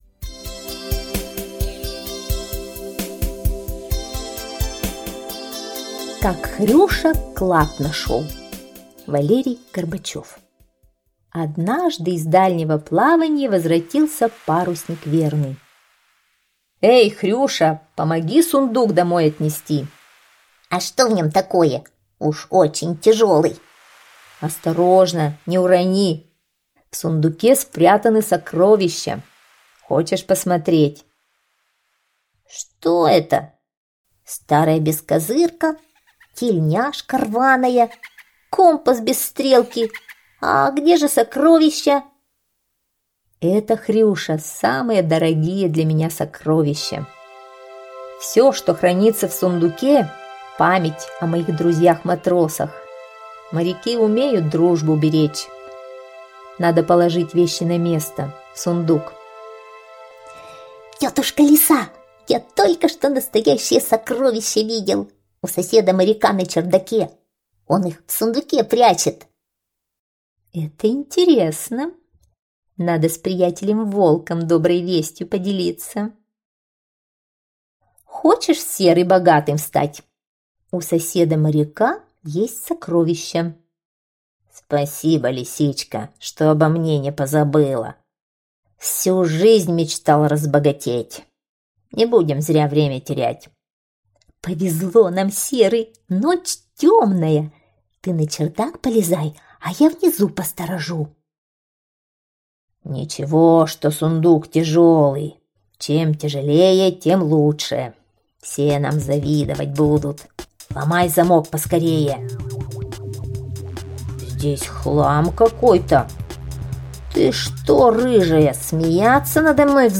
Как Хрюша клад нашёл - аудиосказка Горбачева В. Сказка о том, как Хрюша клад увидел и рассказал о нем Лисе...